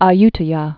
(ä-ytə-yä)